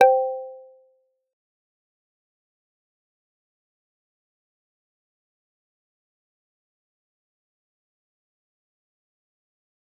G_Kalimba-C5-f.wav